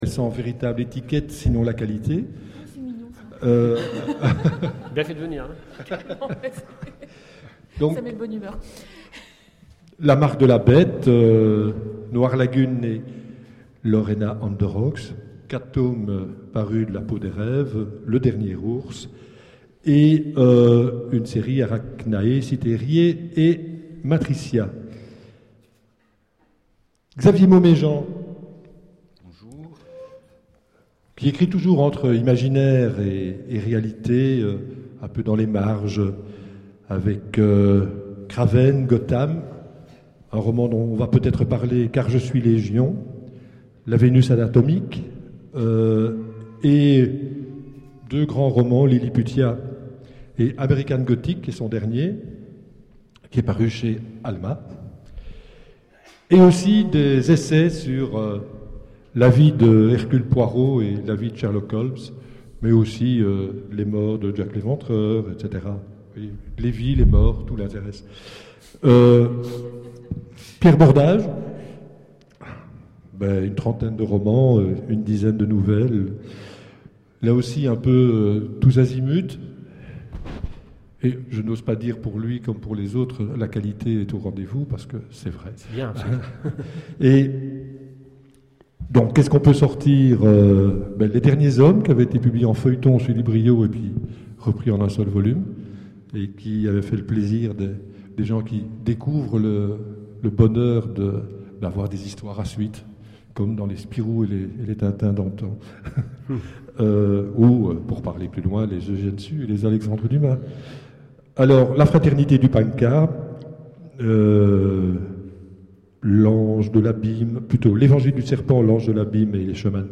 Imaginales 2013 : Conférence Mythes et mythologies...